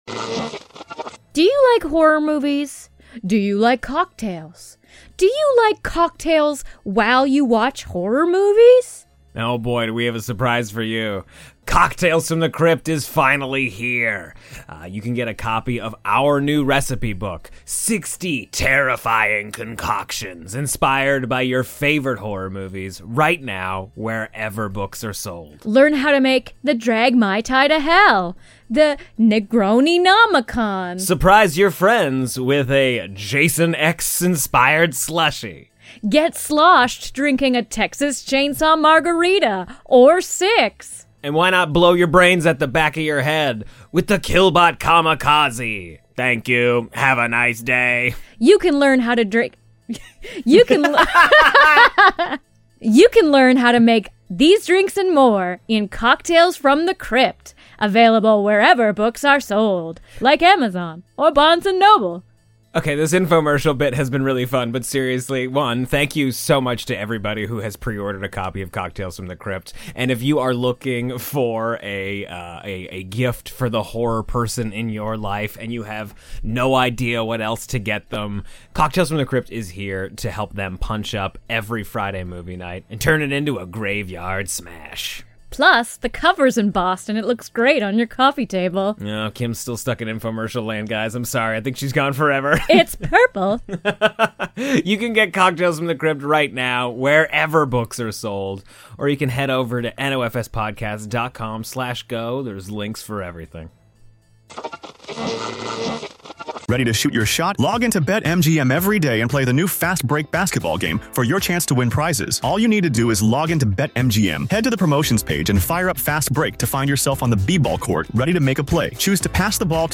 Forget the in-depth analyses and pull up a seat for lively, friendly banter that feels just like a post-movie chat with your best pals at the local bar.